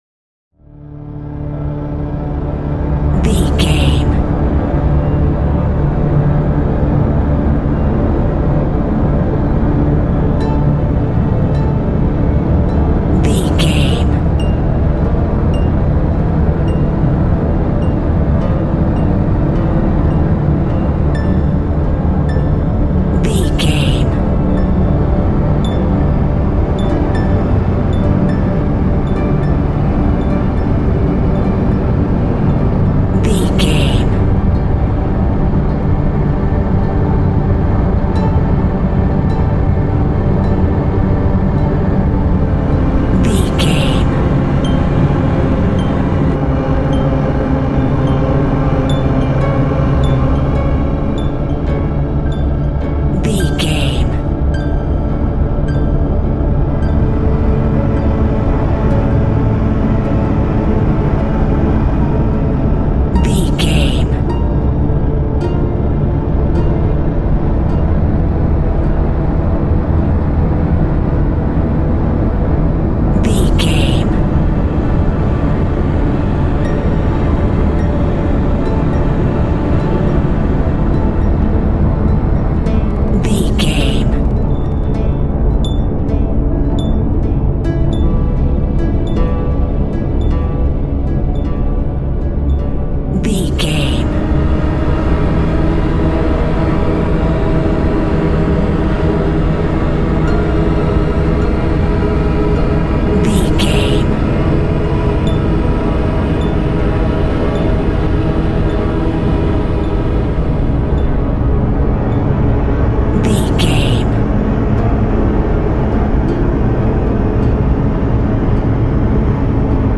Atonal
Slow
tension
ominous
dark
eerie
strings
acoustic guitar
harp
synth
pads